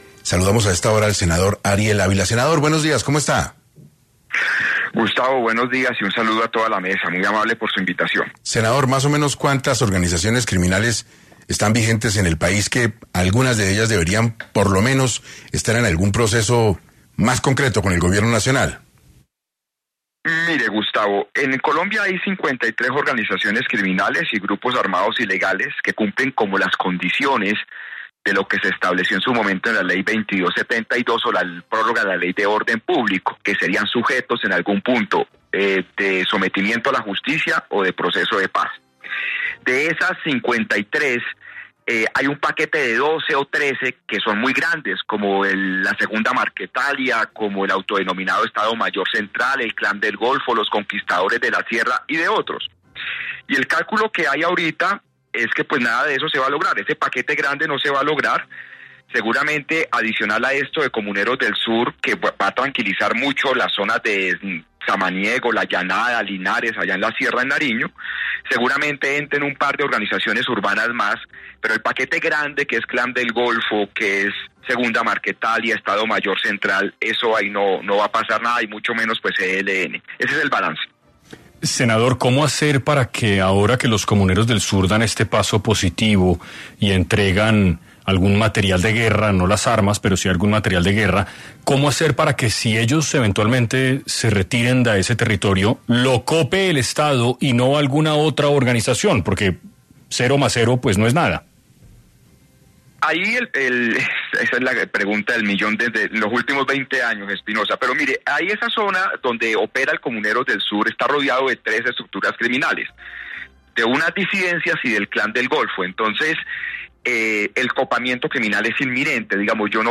Las negociaciones de paz entre los grupos armados y el Gobierno Nacional parecen no haber llegado a ningún acuerdo, ¿qué les espera ahora a los colombianos? En entrevista para 6AM, el senador, Ariel Ávila, respondió esta incógnita